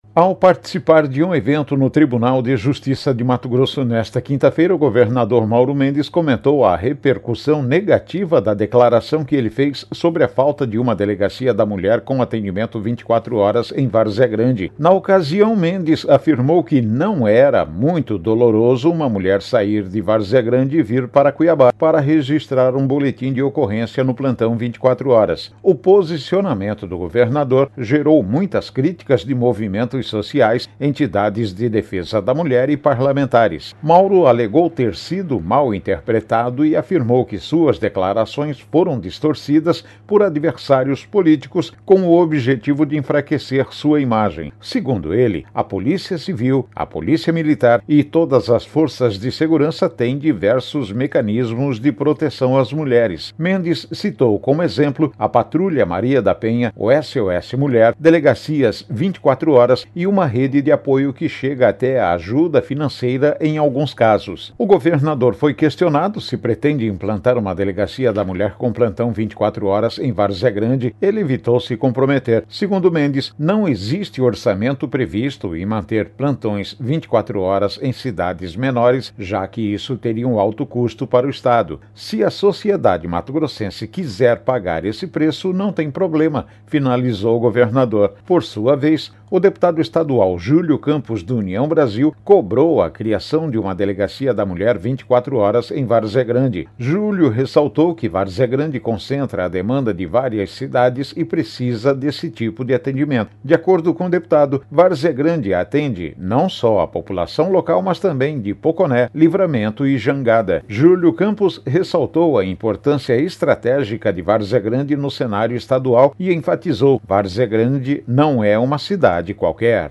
Boletins de MT 03 out, 2025